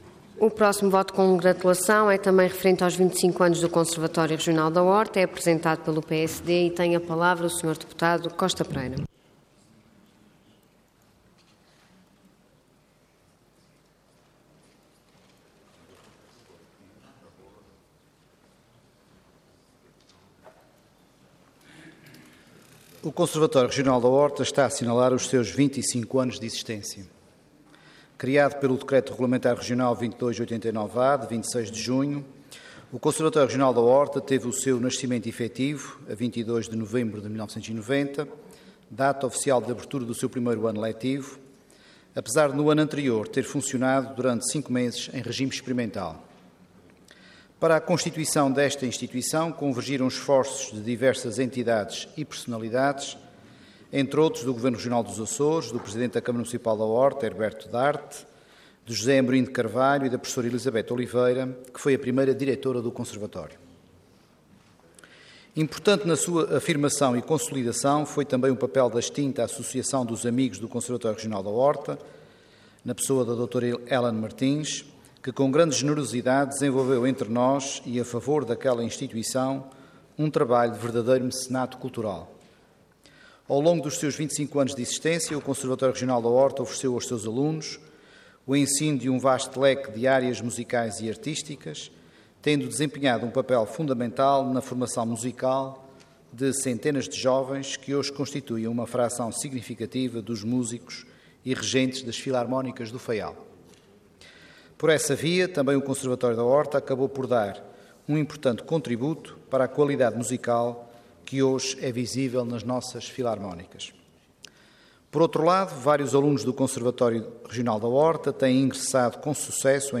Detalhe de vídeo 10 de dezembro de 2015 Download áudio Download vídeo Processo X Legislatura 25 Anos do Conservatório Regional da Horta Intervenção Voto de Congratulação Orador Jorge Costa Pereira Cargo Deputado Entidade PSD